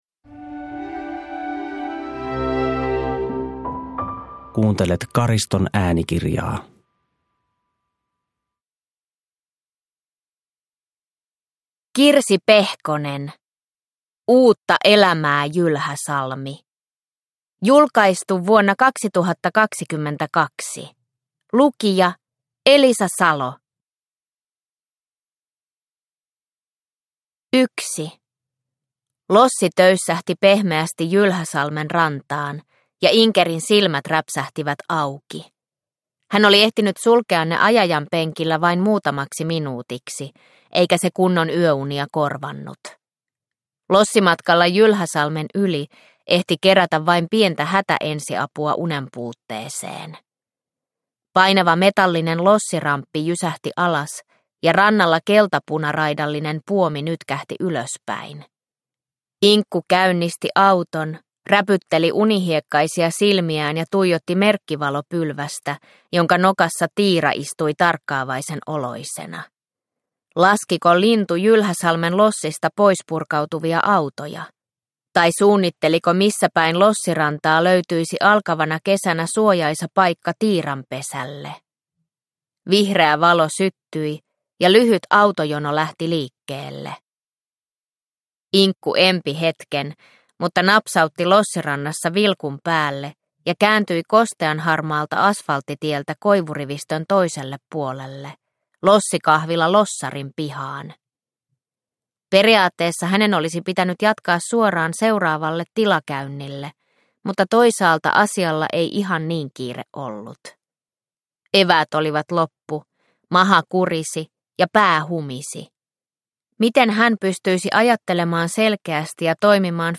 Uutta elämää, Jylhäsalmi – Ljudbok – Laddas ner